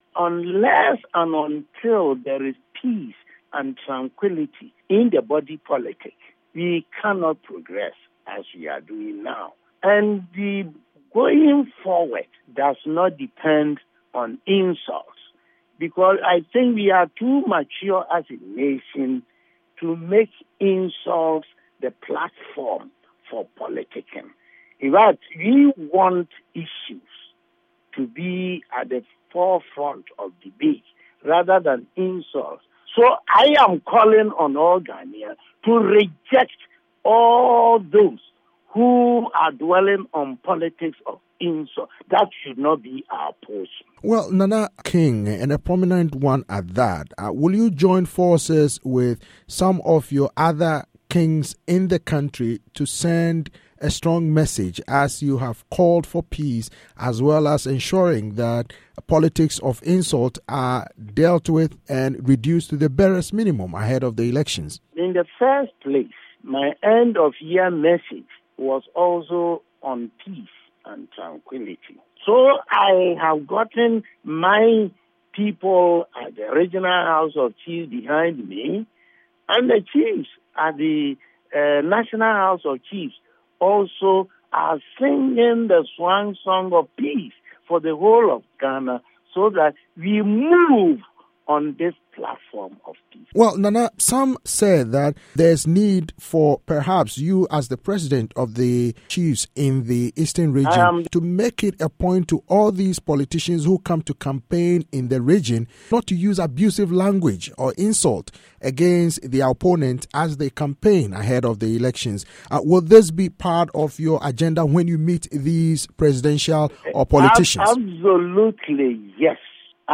initerview